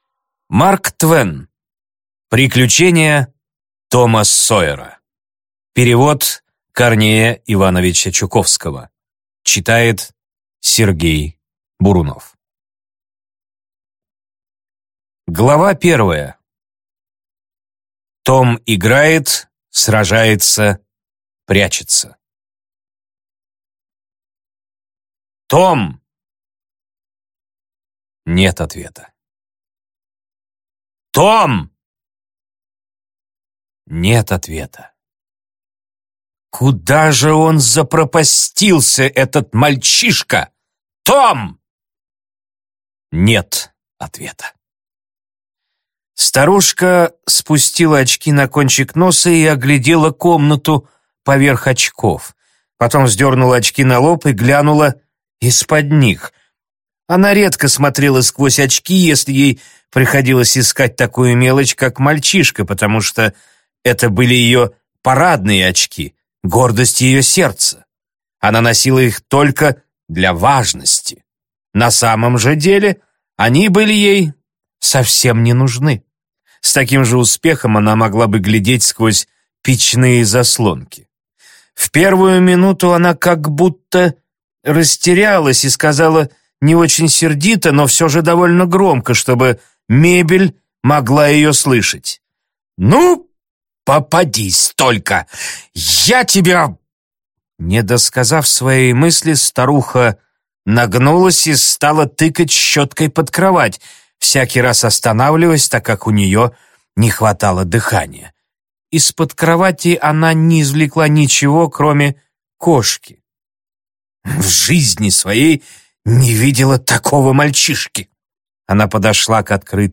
Аудиокнига Приключения Тома Сойера | Библиотека аудиокниг